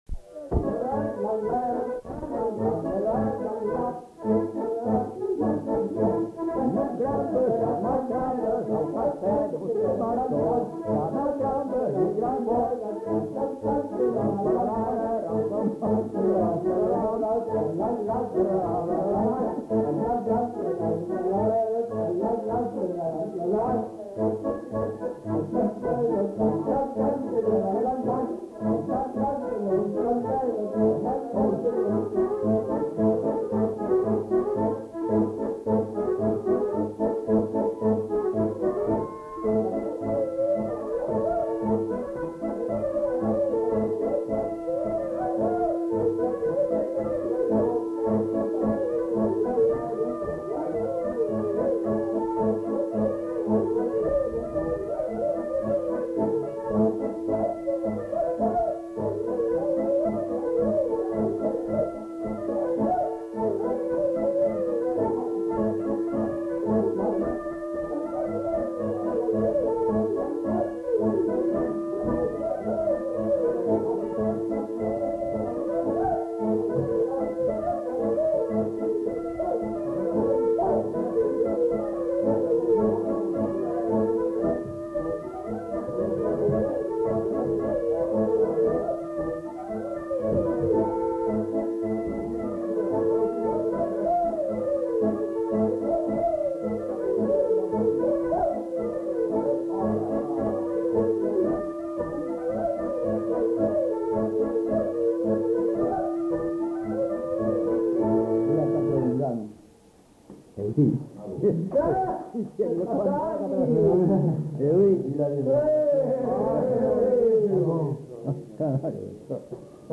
Aire culturelle : Gabardan
Lieu : Estigarde
Genre : morceau instrumental
Instrument de musique : accordéon diatonique ; boha
Danse : congo